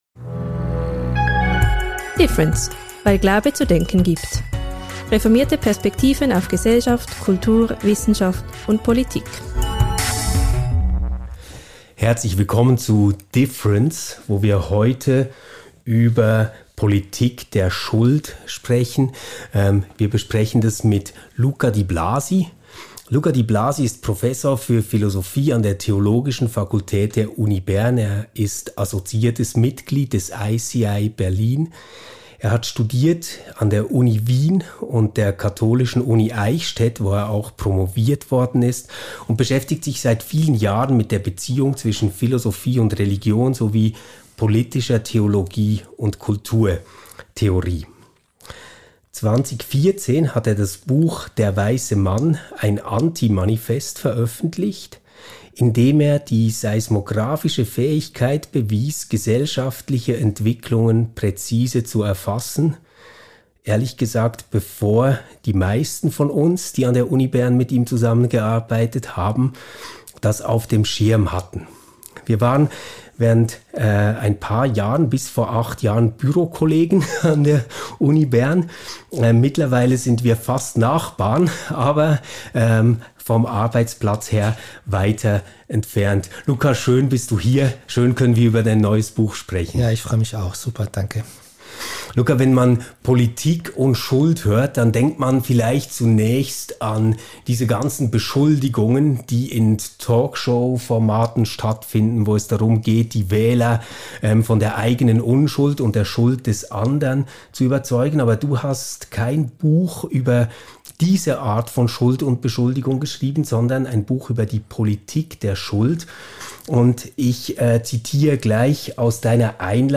In dieser Folge sprechen wir mit dem Philosophen und Autor